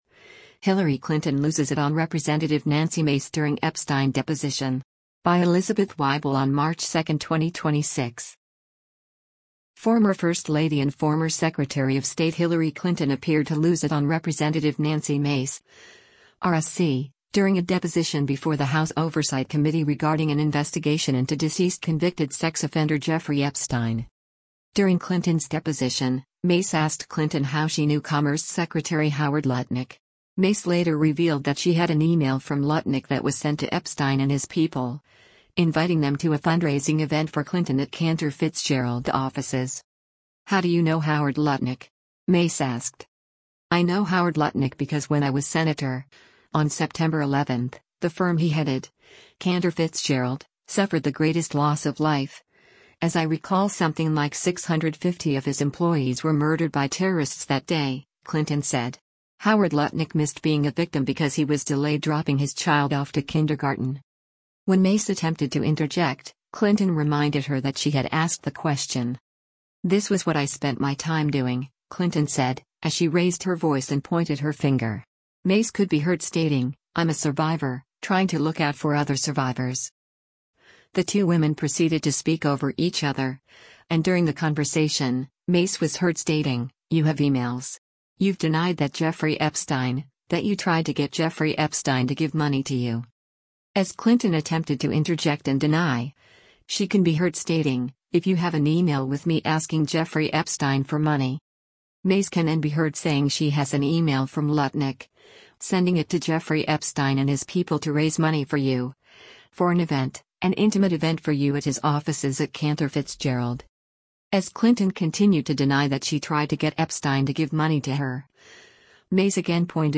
“This was what I spent my time doing,” Clinton said, as she raised her voice and pointed her finger.
The two women proceeded to speak over each other, and during the conversation, Mace was heard stating, “You have emails. You’ve denied that Jeffrey Epstein — that you tried to get Jeffrey Epstein to give money to you.”